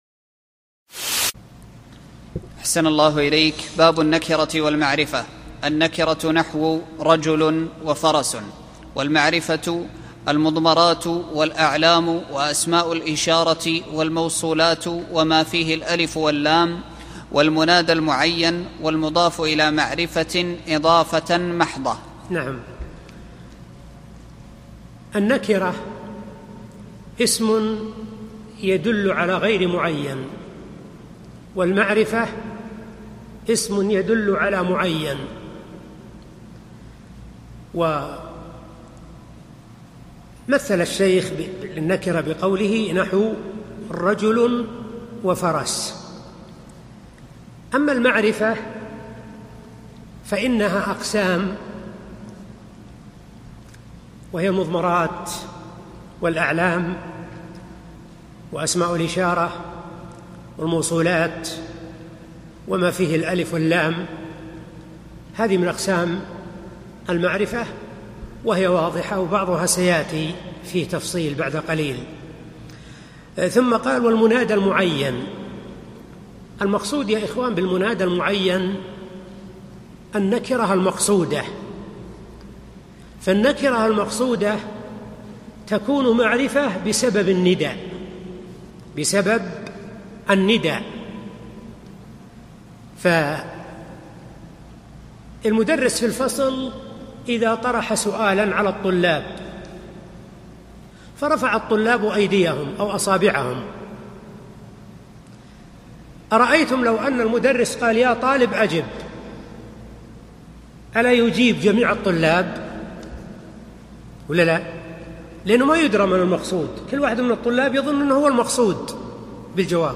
الدورة العلمية 15 المقامة في جامع عبداللطيف آل الشيخ في المدينة النبوية لعام 1435
الدرس الخامس